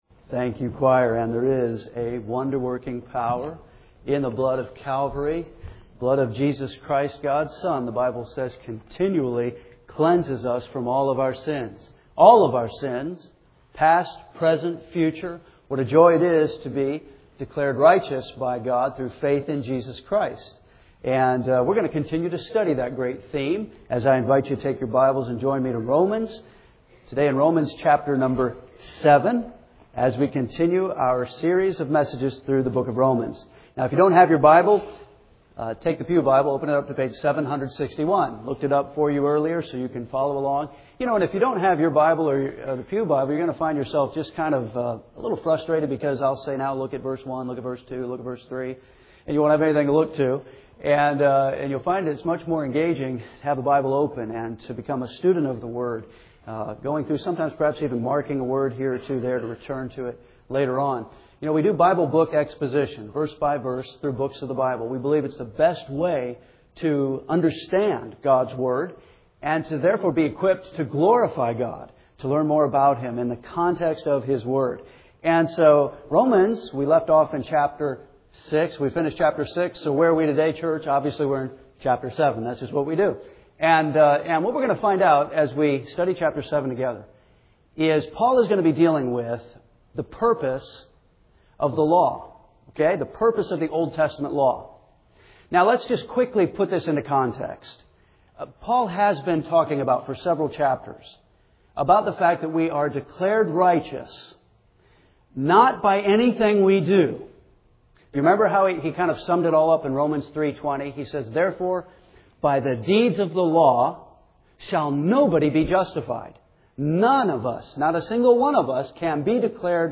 We’re continuing our series of expository messages through the Book of Romans, verse-by-verse, chapter-by-chapter.